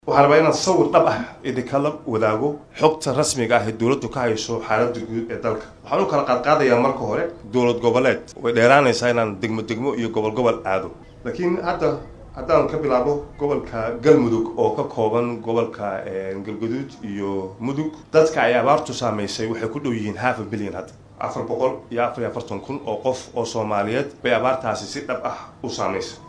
Guddiga Gurmadka abaaraha ee dalka Soomaaliya ayaa kulan wacyigelin ah la qaatay qaar ka mid ah dhallinyarada gobolka Banaadir.